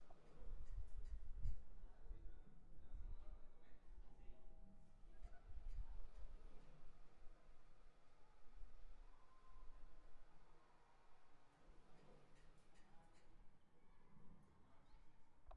Lift
描述：A ride in a lift. Inside microphones of a PCMD100.
标签： elevator fieldrecording lift
声道立体声